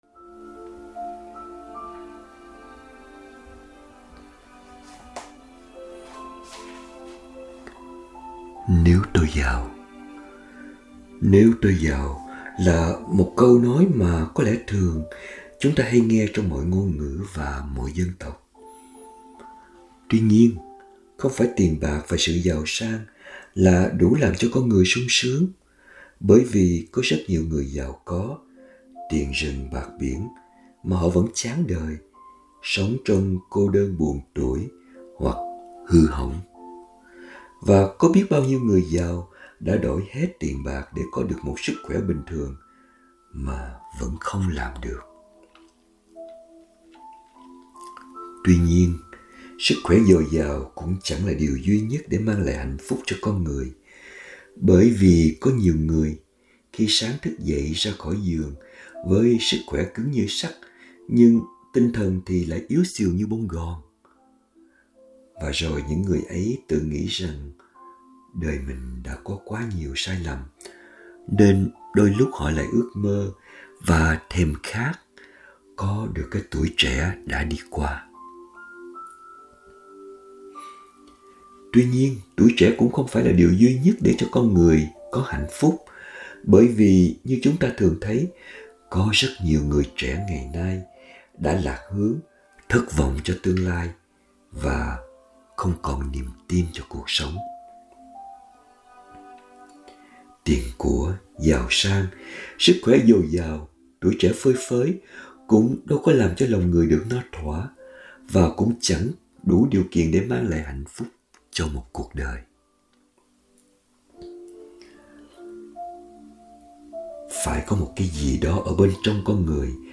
2025 Audio Suy Niệm https